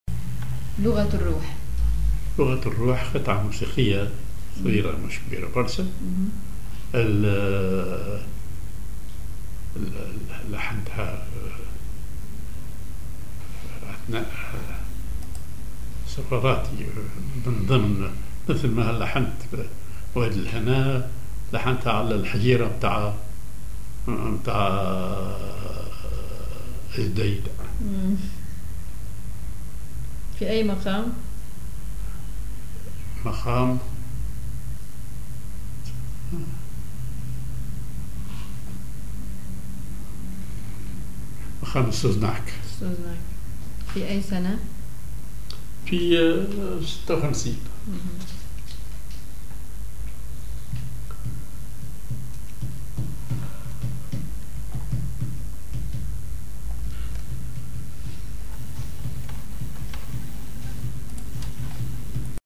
Maqam ar هزام
معزوفة